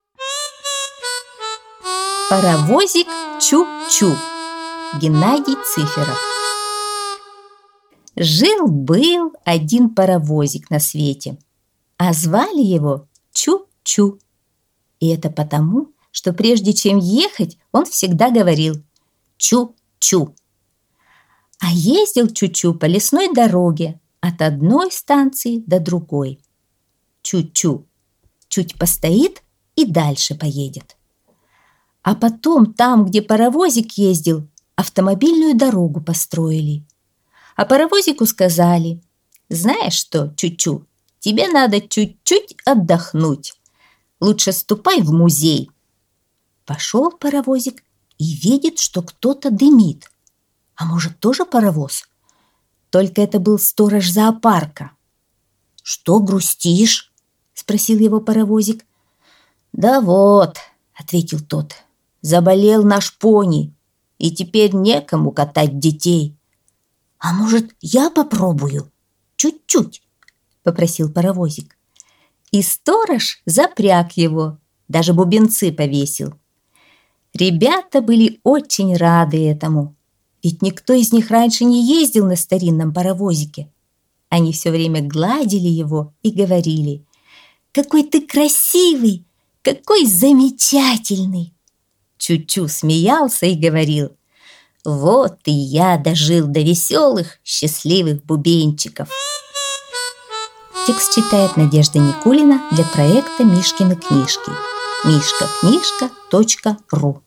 Аудиосказка «Паровозик Чу-Чу»